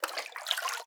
SFX_GettingWater_01.wav